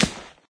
plasticgrass2.ogg